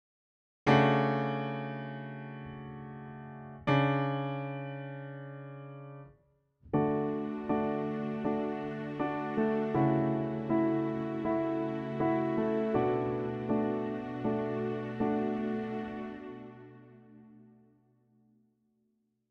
If you change the "D" in treble chord to an "E" it removes the offending dissonance and it creates a little inner-voice movement.
chord_fix.mp3